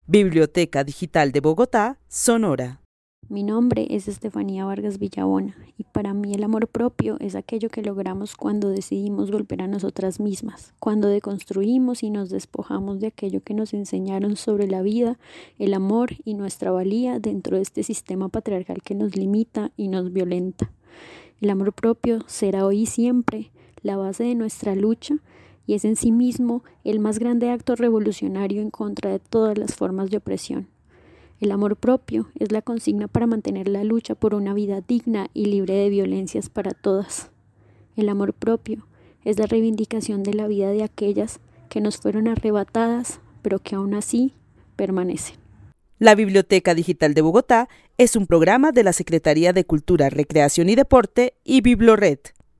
Narración oral de una mujer que vive en la ciudad de Bogotá para quien el amor propio es la deconstrucción de lo que ha enseñado el sistema patriarcal como acto revolucionario en contra de todas las formas de opresión. Describe el amor propio como la consigna para mantener la lucha por una vida digna y libre de violencias. El testimonio fue recolectado en el marco del laboratorio de co-creación "Postales sonoras: mujeres escuchando mujeres" de la línea Cultura Digital e Innovación de la Red Distrital de Bibliotecas Públicas de Bogotá - BibloRed.
Narrativas sonoras de mujeres